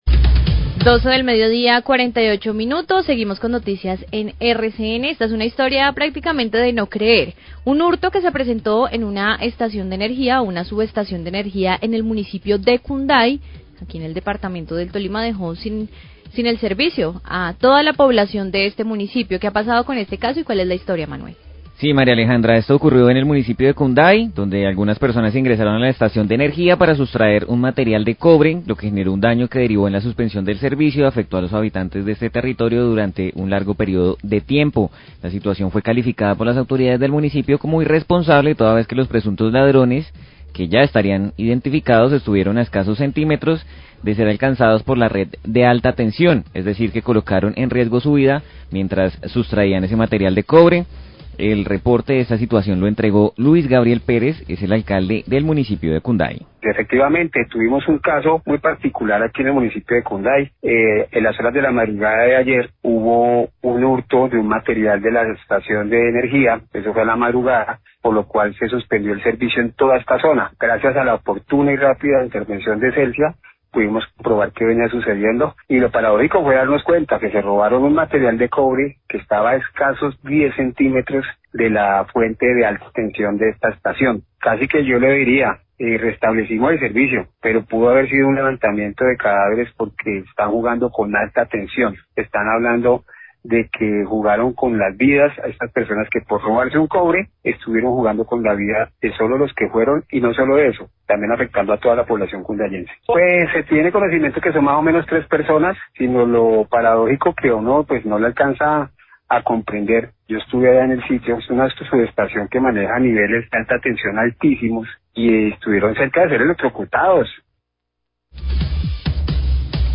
Alcalde Cunday habla del robo de cobre en subestación de energía y rápida respuesta de Celsia
Radio
El alcalde de Cunday, Luis Gabriel Pérez, habla del hurto de material de cobre que realizaron tres inescrupulosos en la subestación de energía de este municipio y que generó el corte de energía.